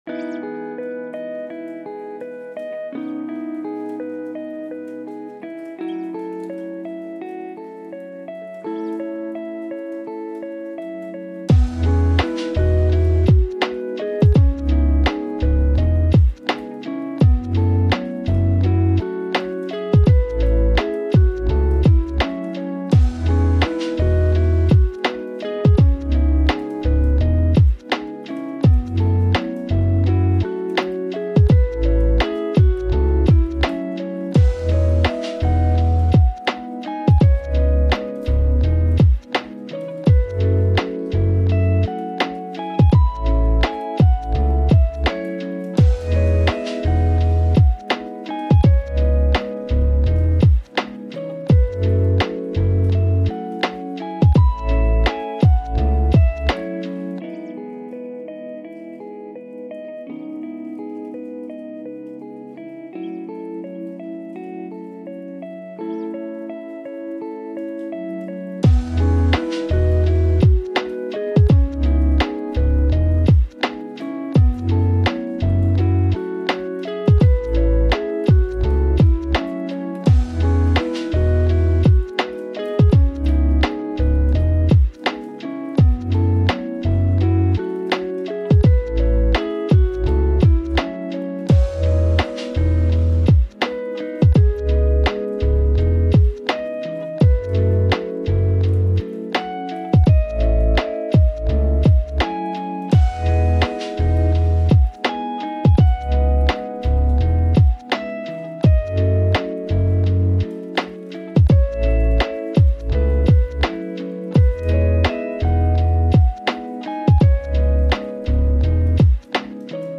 your ultimate destination for calming vibes, chill beats